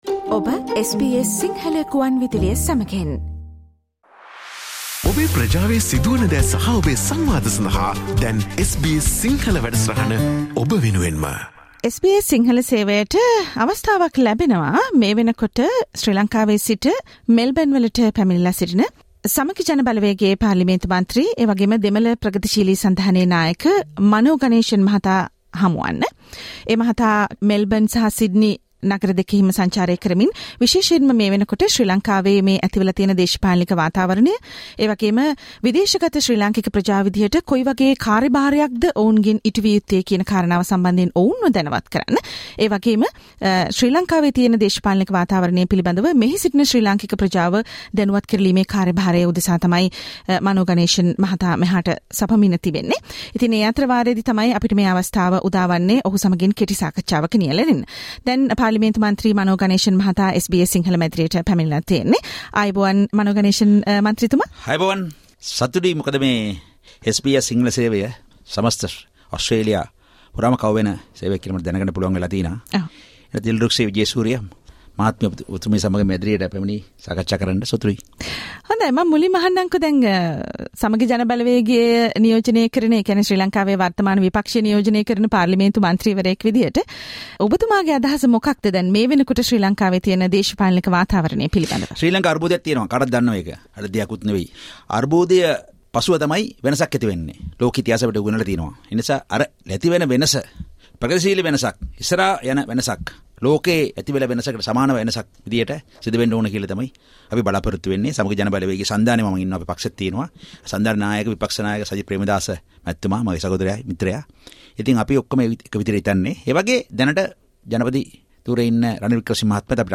MP Mano Ganesan, parliament Member of the Samagi Jana Balawegaya and leader of the Tamil Progressive Alliance - Sri Lanka, speaks to the SBS Sinhala Radio about the current political situation in Sri Lanka during his visit to Australia...
Sri Lankan MP from the opposition, Mr. Mano Ganeshan visited SBS Sinhala studio, 20 September 2022